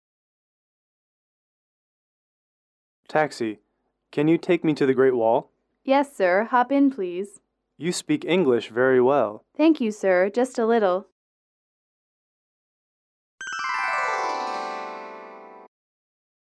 英语口语情景短对话14-1：打车去长城(MP3)